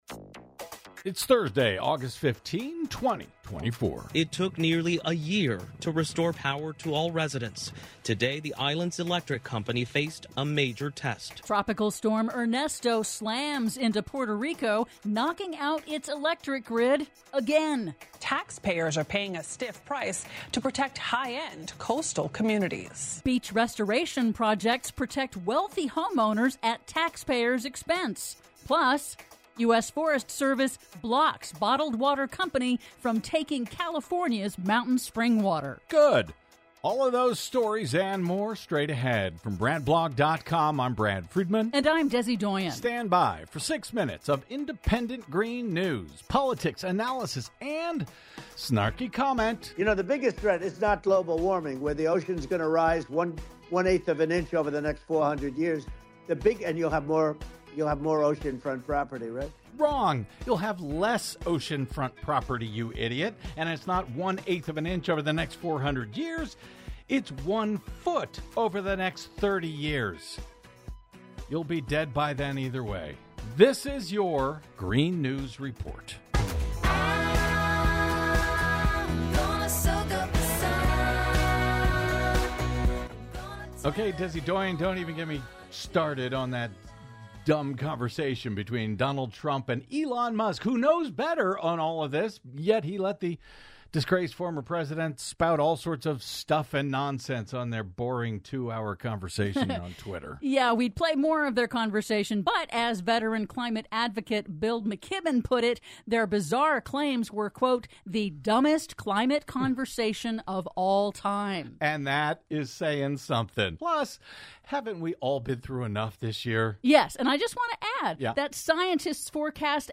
IN TODAY'S RADIO REPORT: Hurricane Ernesto knocks out power to Puerto Rico, again; Beach restoration projects protect wealthy homeowners at taxpayers' expense; PLUS: U.S. Forest Service blocks bottled water company from taking California's mountain spring water... All that and more in today's Green News Report!